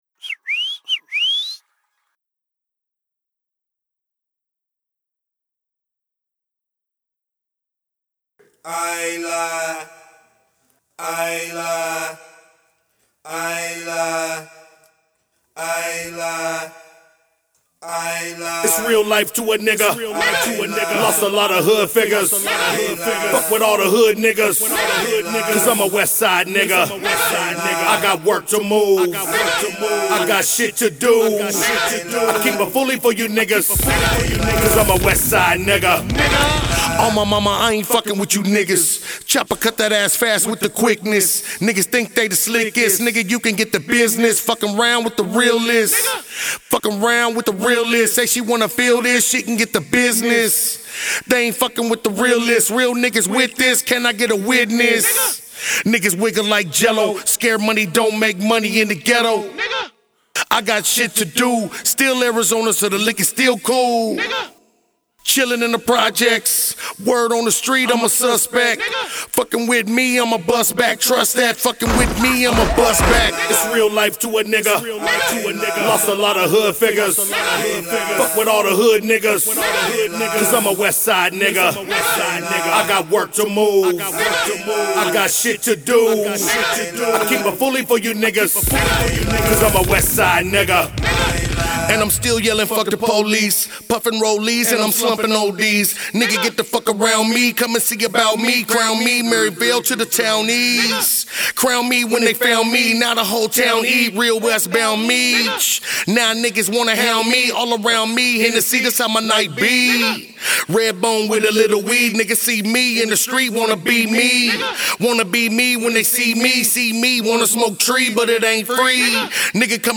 IAINTLYINACAPELLA